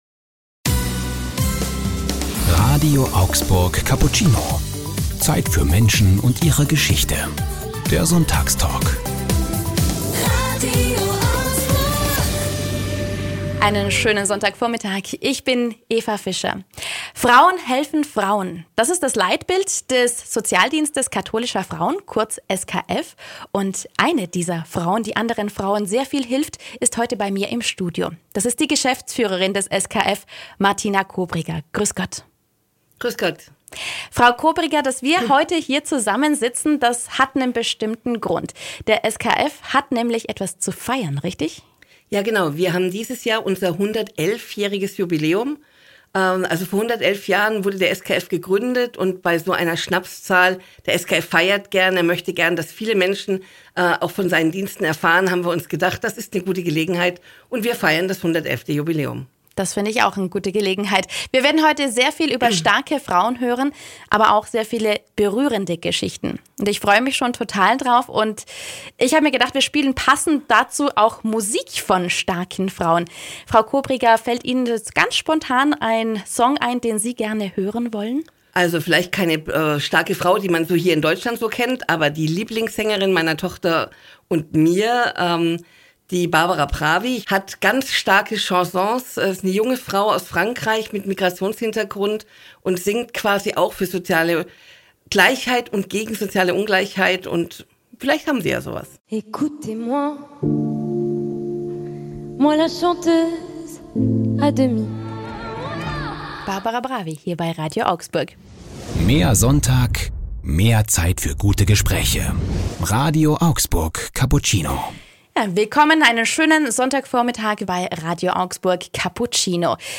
Unter diesem Motto stand unser Sonntagstalk hier bei Radio Augsburg am 1. Oktober.